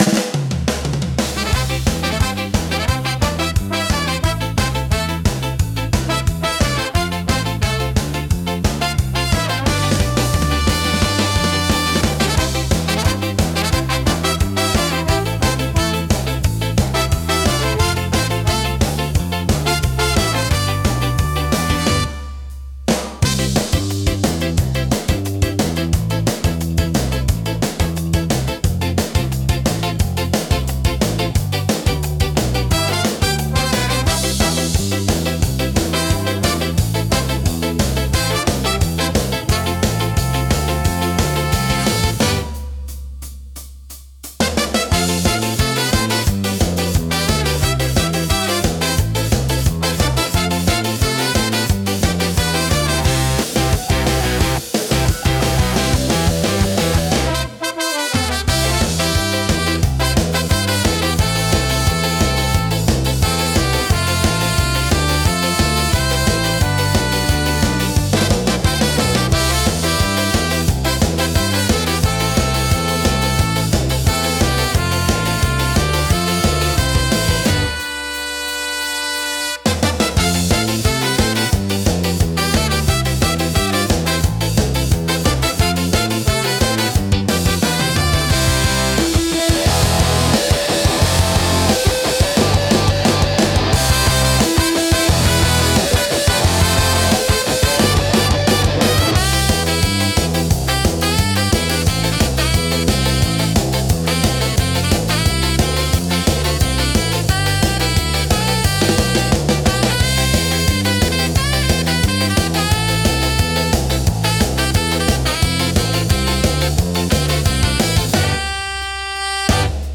元気で勢いのあるサウンドが聴く人の興奮を高め、活気溢れる空間を作り出します。